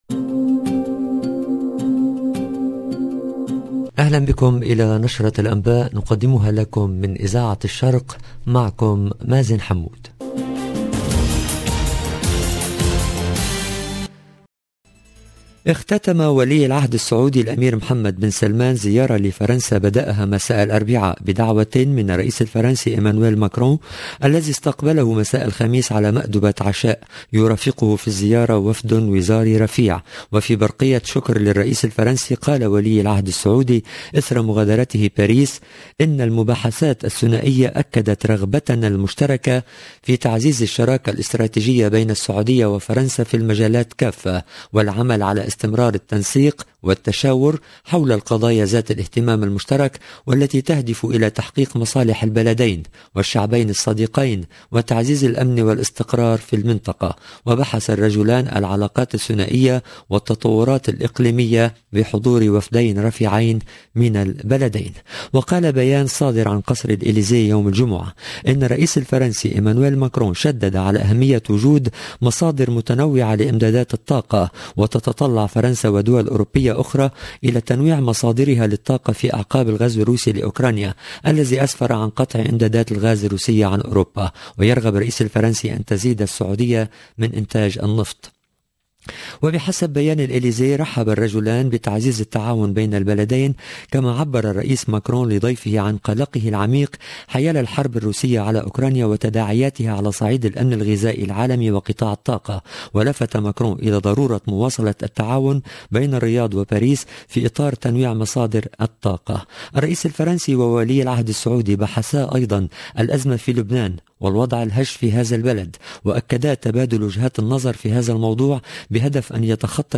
LE JOURNAL DU SOIR EN LANGUE ARABE DU 29/07/22